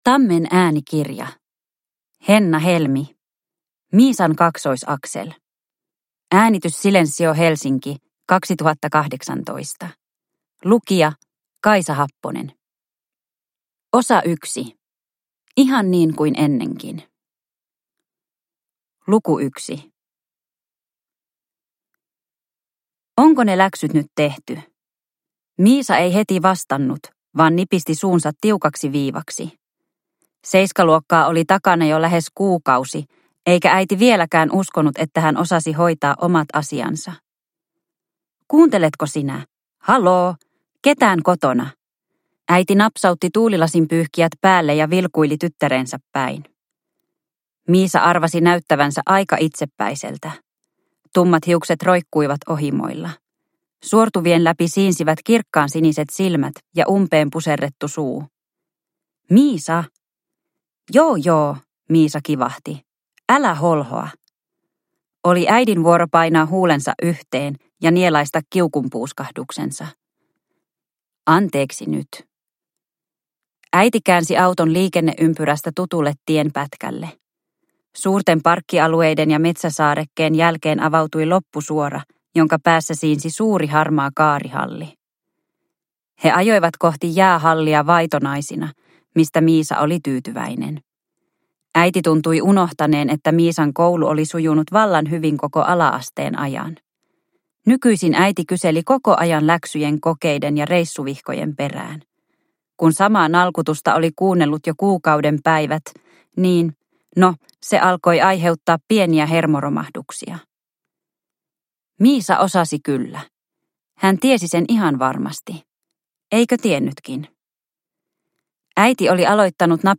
Miisan kaksoisaxel – Ljudbok – Laddas ner